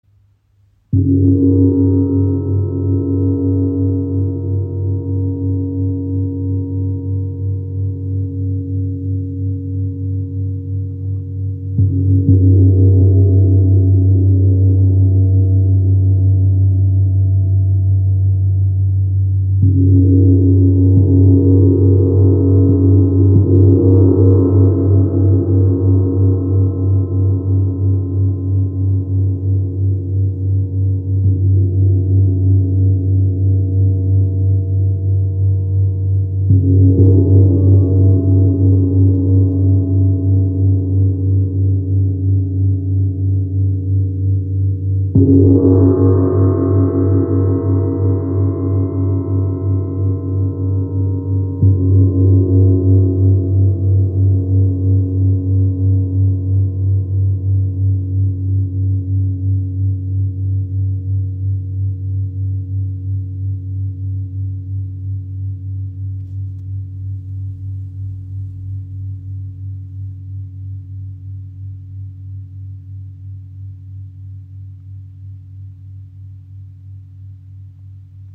Frequenz: 194,71 Hz (G2) • 80 cm • Edelstahl
• Icon Kompakter Gong mit kraftvollem, resonantem Klang
Sideral Day Gong – Handgefertigtes Unikat aus Edelstahl, gestimmt auf 194,71 Hz (G2) nach der Kosmischen Oktave.
Sein klarer, resonanter Klang wirkt beruhigend und zentrierend, unterstützt Meditation, fördert innere Balance und hilft, sich mit den natürlichen Zyklen des Lebens in Einklang zu bringen.